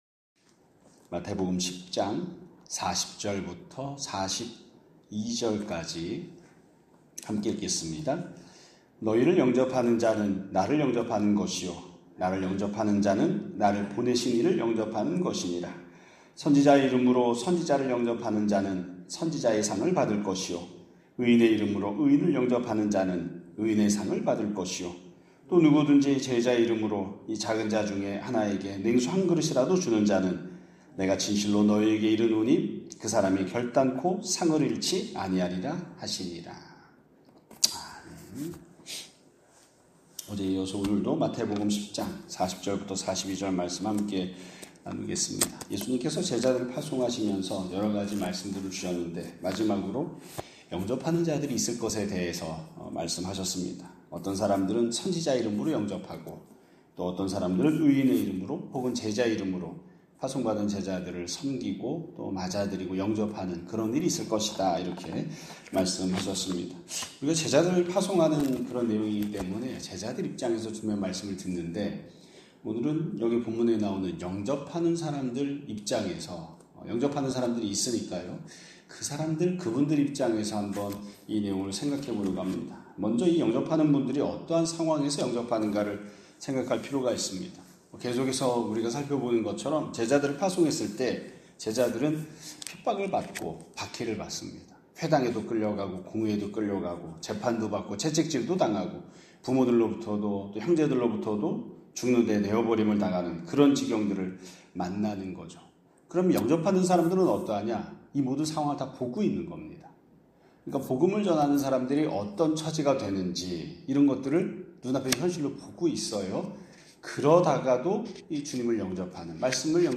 2025년 8월 21일 (목요일) <아침예배> 설교입니다.